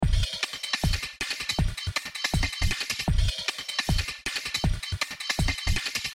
RMX est plutôt orienté batterie que percussion, avec du très gros son qui groove bien.
rmx1.mp3